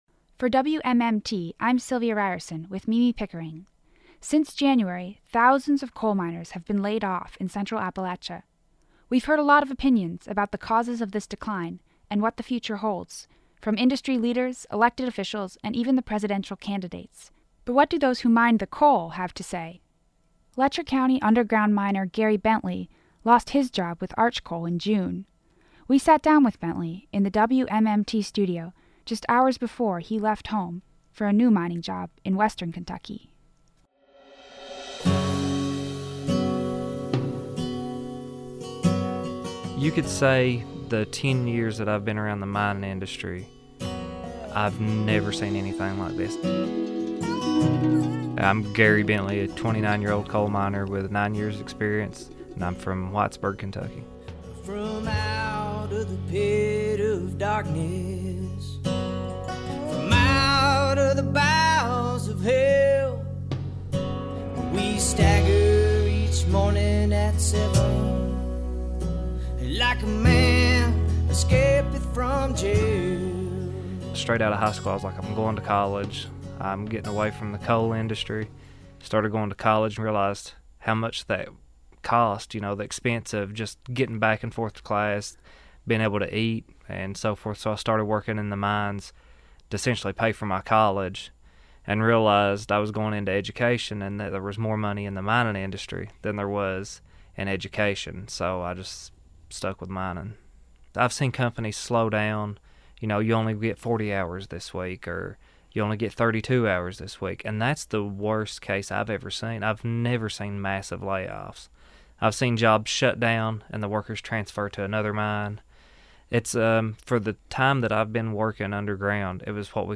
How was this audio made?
in the WMMT studio